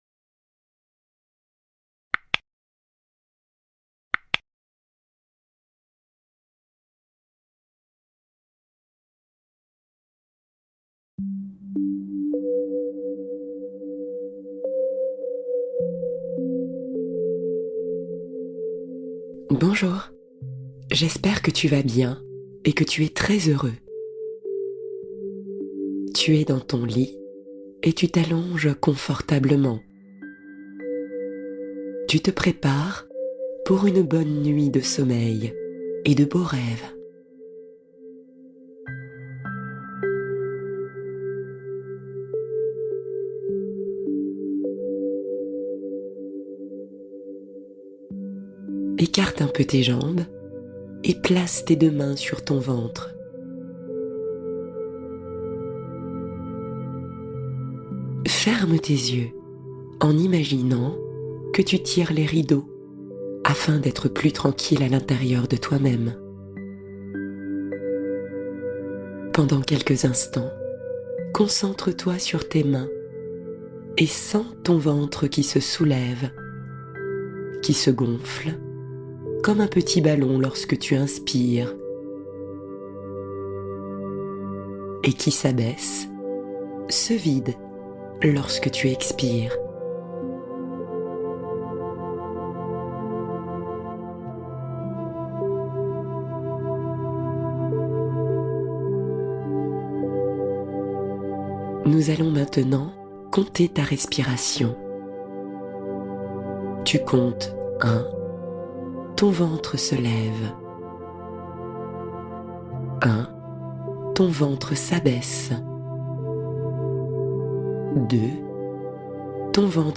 Danse des Fées : Conte onirique pour endormir les enfants avec magie